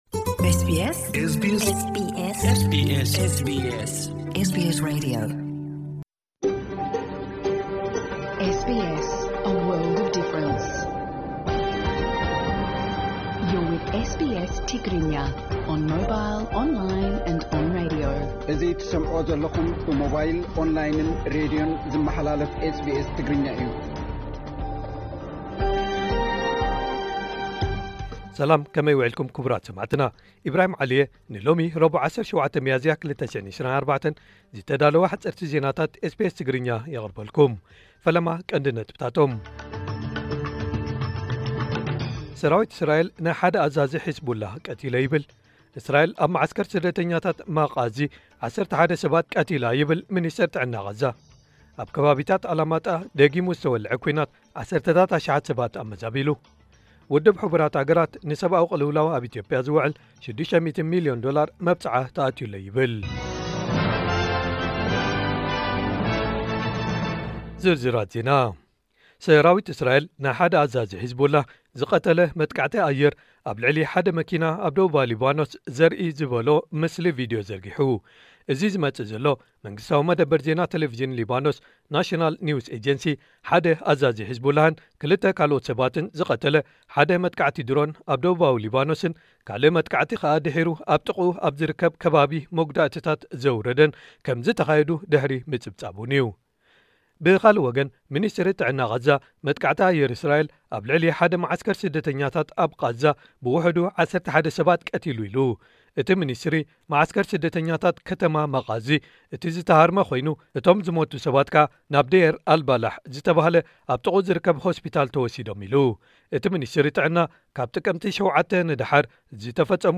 ሓጸርቲ ዜናታት ኤስ ቢ ኤስ ትግርኛ (17 ሚያዝያ 2024)